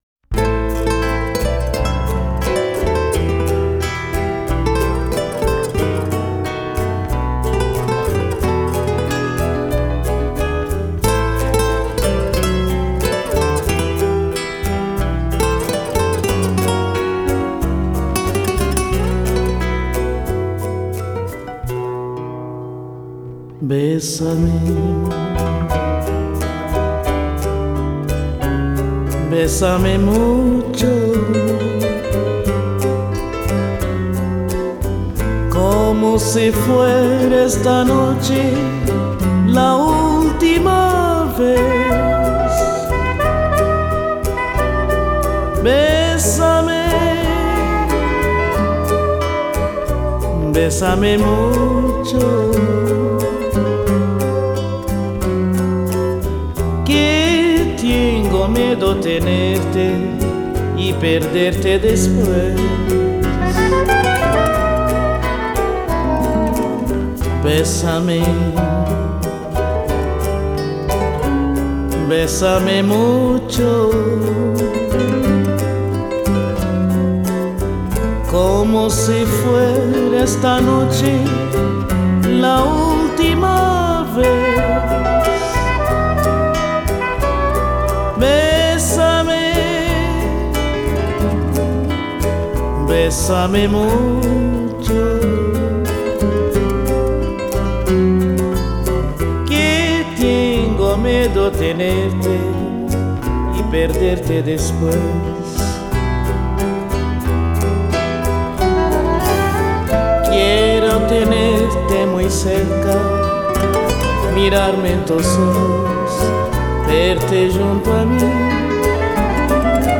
Под музыку, напоминающую смесь шансонных традиций и босановы